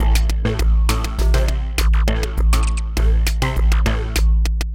Update (17-June-2017) Rough arrangement, etc.